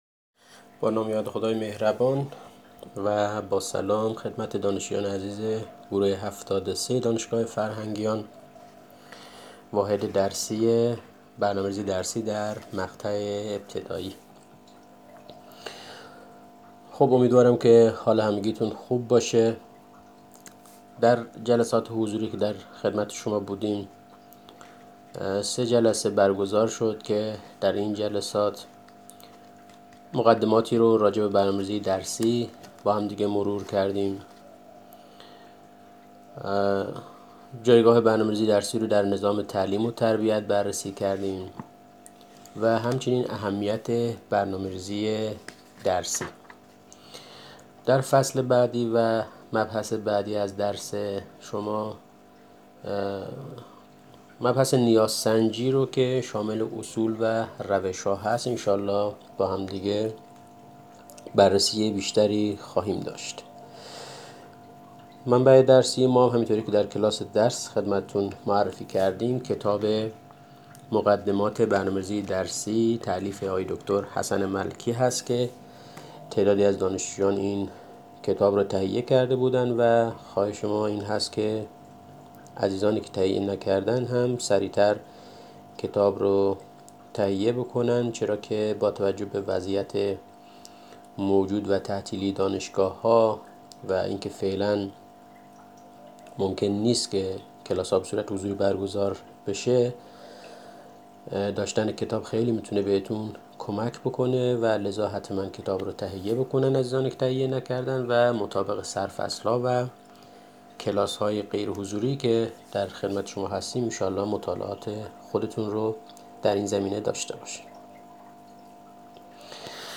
درس برنامه ریزی درسی آموزشی